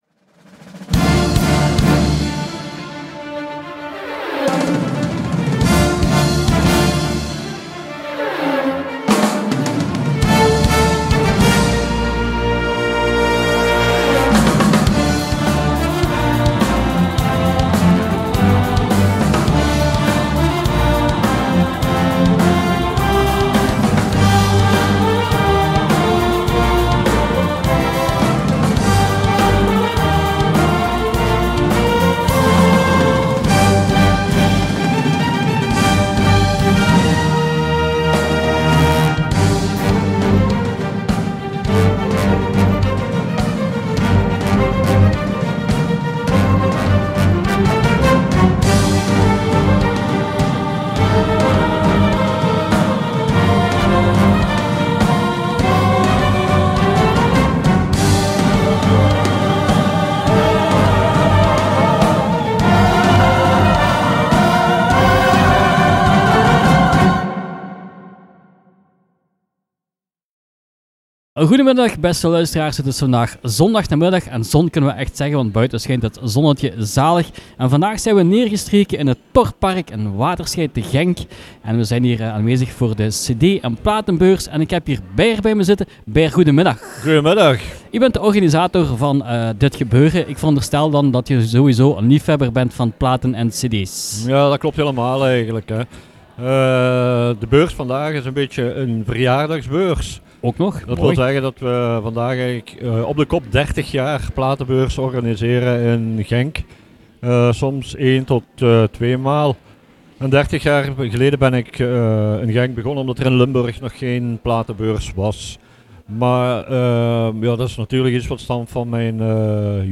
Hier zijn de interviews her te beluisteren die we op zondag 8 oktober gemaakt hebben .